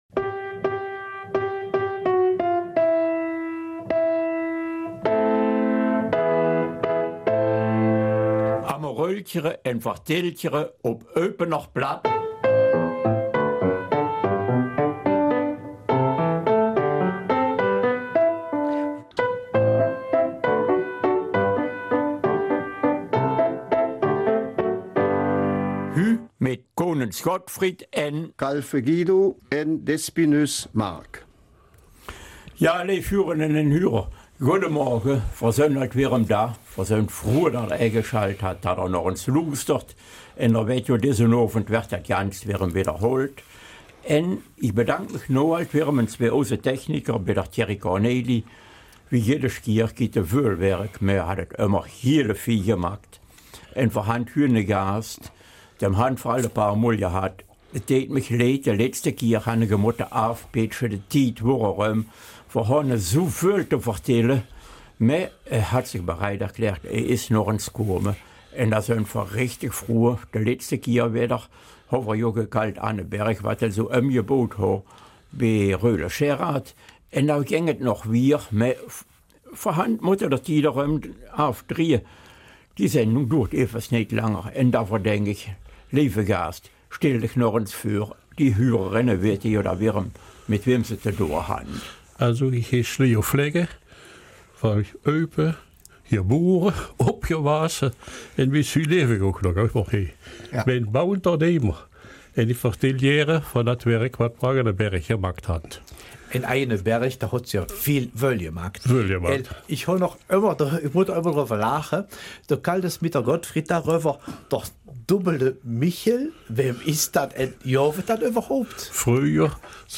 Gut, dass der Tontechniker die Mikros noch nicht ausgeschaltet hatte.
Eupener Mundart